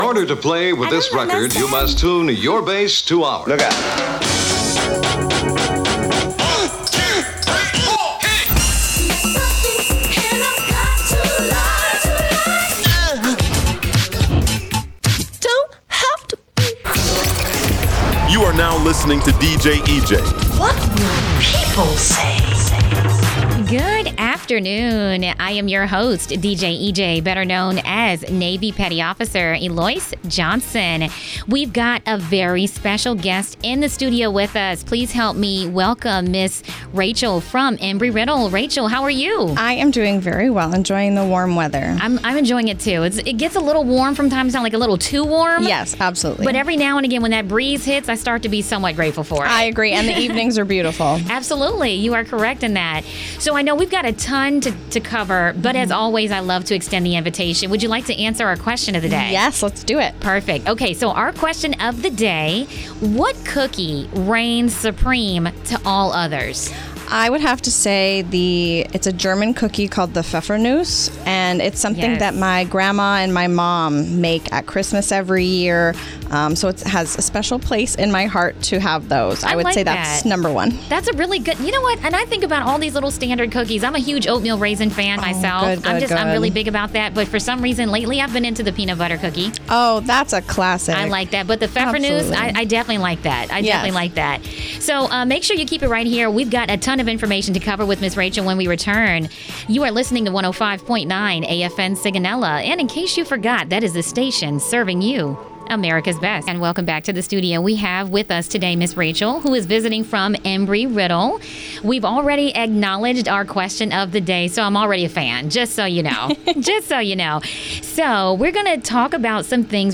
Embry Riddle Interview 24JUL09 AFN Sigonella